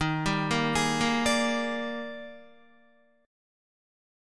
EbM11 Chord
Listen to EbM11 strummed